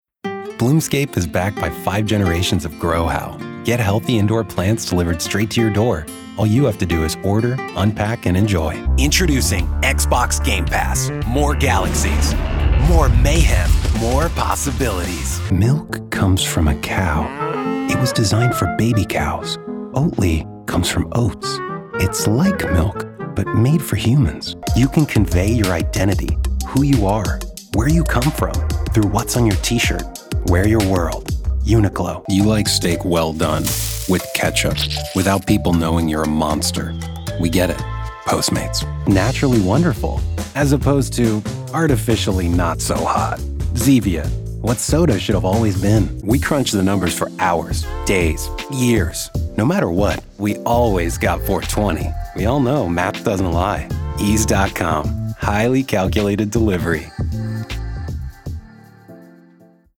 🎙 Voiceover
Commercial Demo Reel